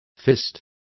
Complete with pronunciation of the translation of fists.